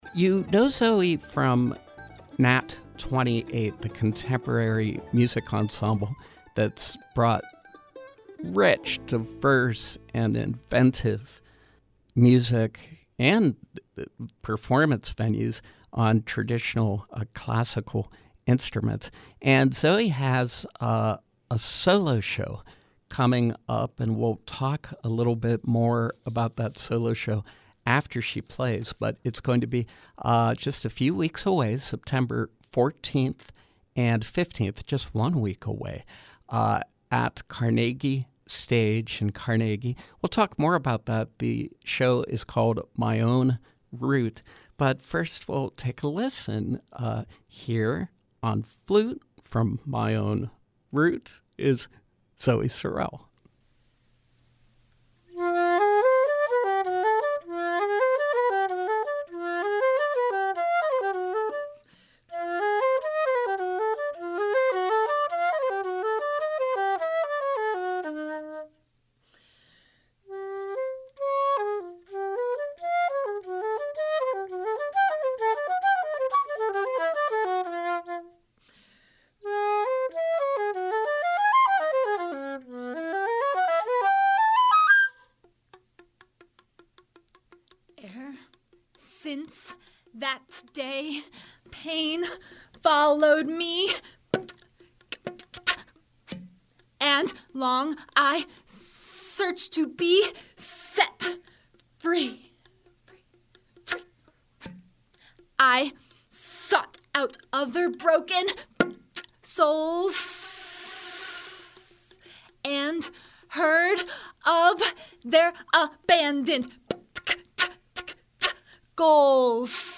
Flutist
Interviews , Performance